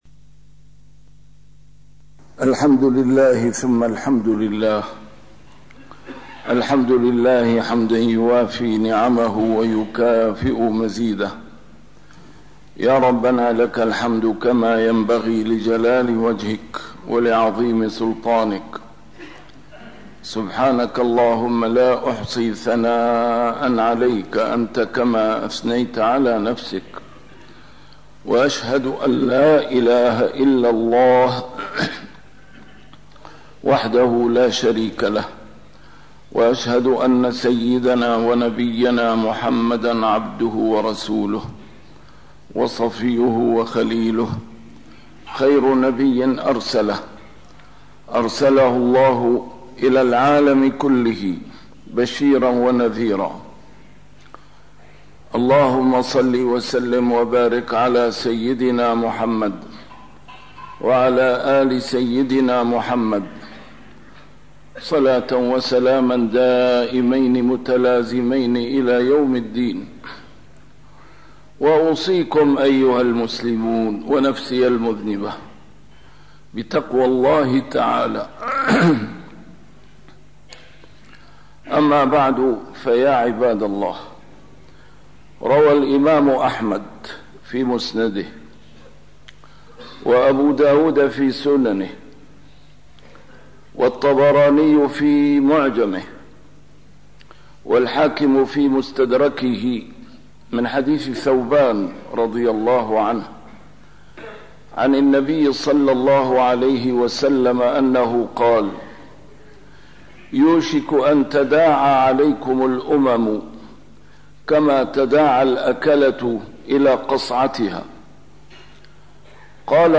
A MARTYR SCHOLAR: IMAM MUHAMMAD SAEED RAMADAN AL-BOUTI - الخطب - لهذا ضربت على الأمة الذلة والمسكنة